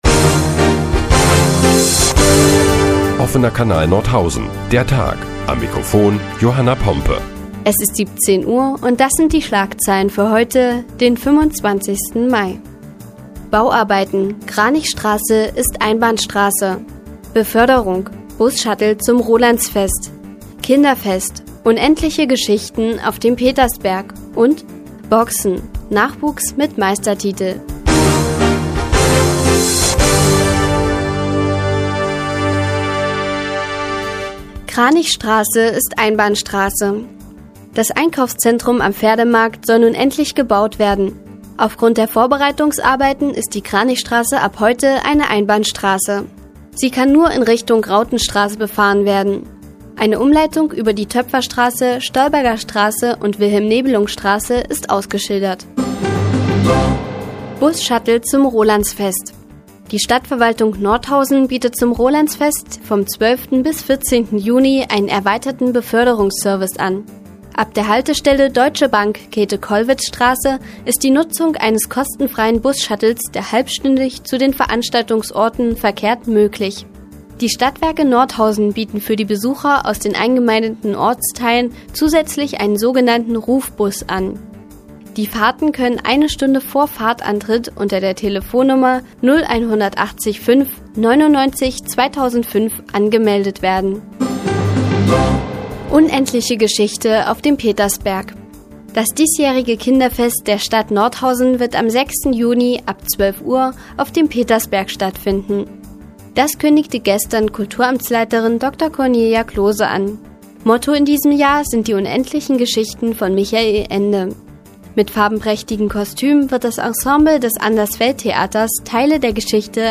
Die tägliche Nachrichtensendung des OKN ist nun auch in der nnz zu hören. Heute geht es unter anderem um Busshuttle zum Rolandsfest und unendliche Geschichten zum Kinderfest.